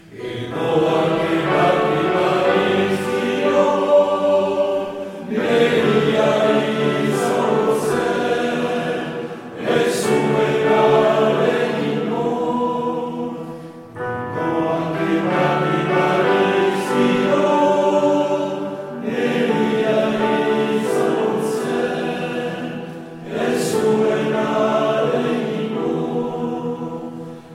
Chants traditionnels